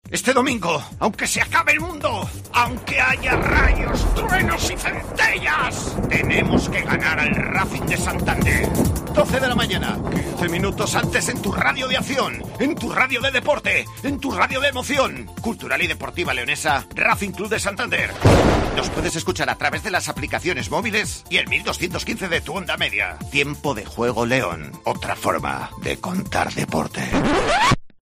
Escucha la cuña promocional del partido Cultural-Racing Santander el día 07-11-21 a las 12:00 h en el 1.215 OM